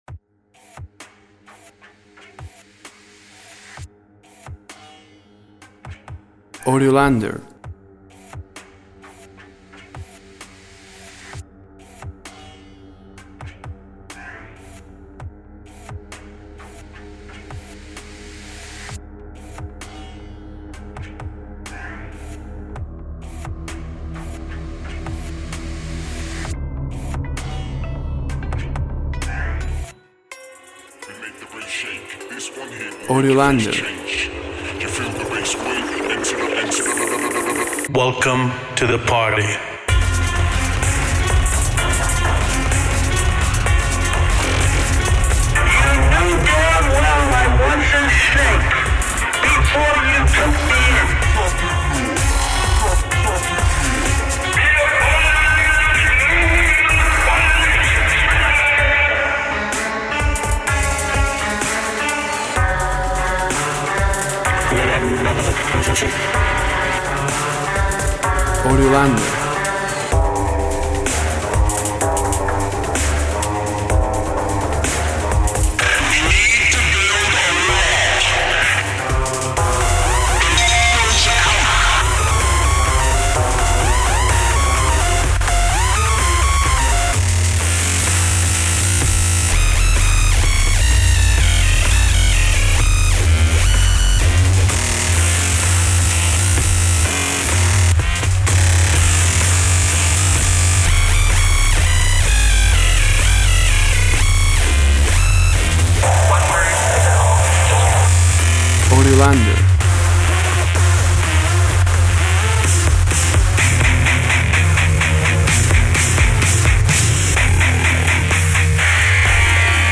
Electronic sounds and power drum machines.
Tempo (BPM) 100